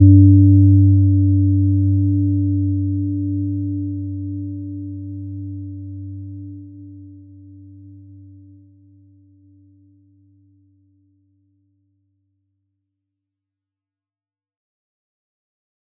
Gentle-Metallic-1-G2-mf.wav